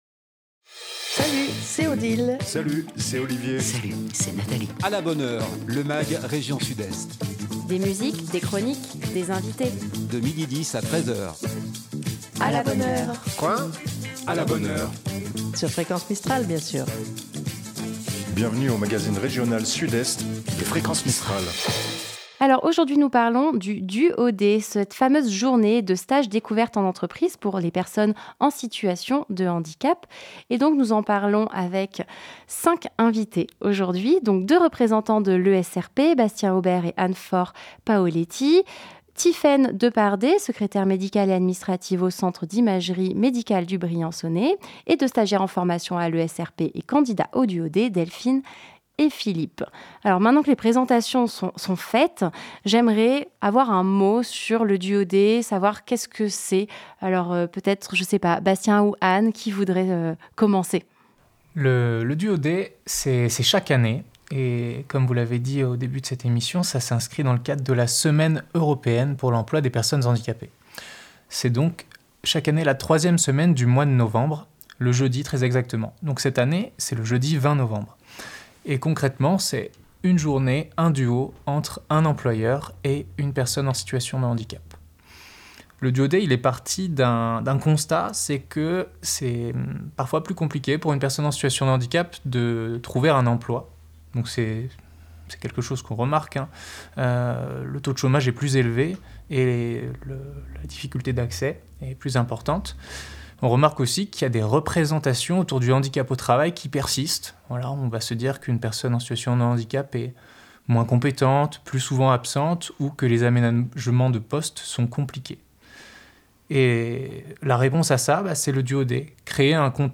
" Bienvenue dans le magazine région Sud-Est de Fréquence Mistral !